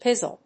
• IPA: /ˈpɪzl̩/, enPR: pĭz'əl